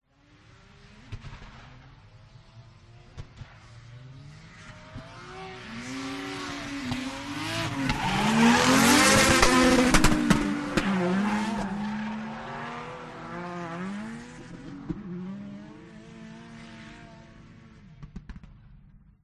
Тихий гул выхлопа Бентли